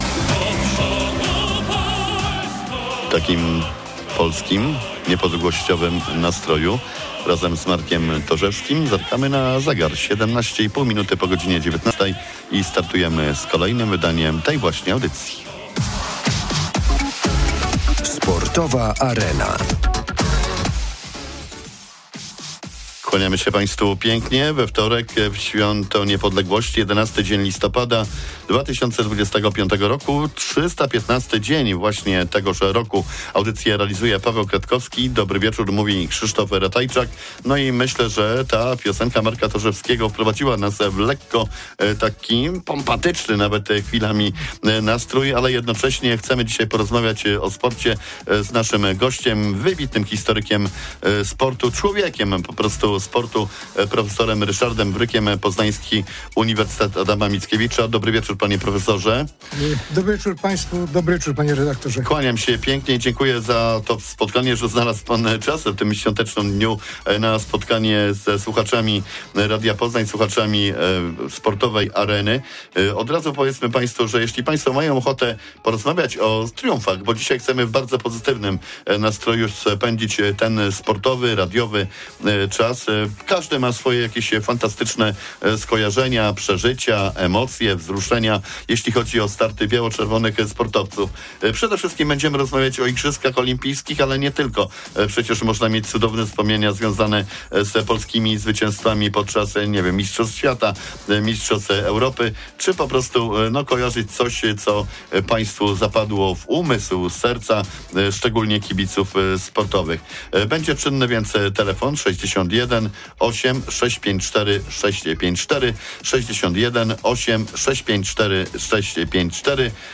Sportowa Arena-emisja live 11 listopada 2025, g.19.15. W Święto Niepodległości o sukcesach biało-czerwonych sportowców na przestrzeni 107 lat .